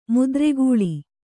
♪ mudre gūḷi